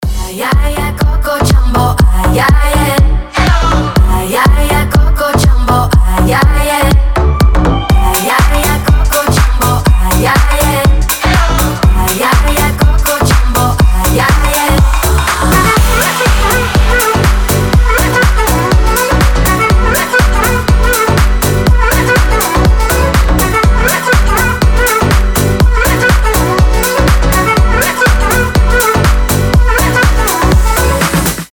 • Качество: 256, Stereo
dance
future house
house